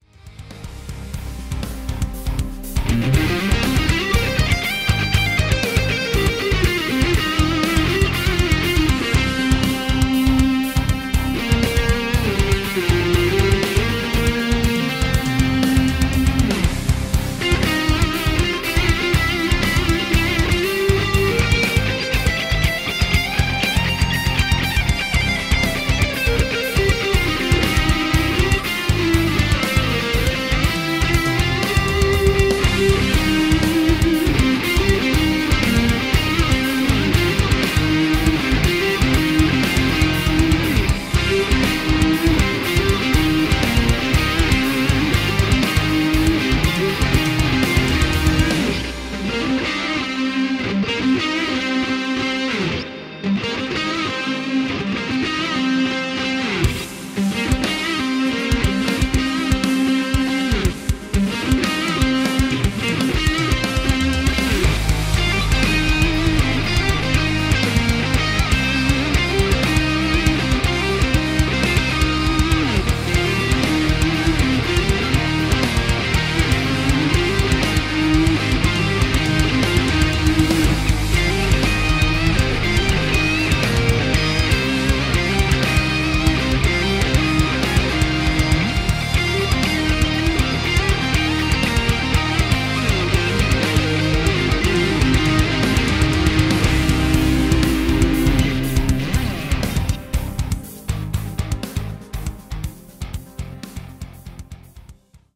3/6 x guitars layered, 1 x bass, drums, and ambient effects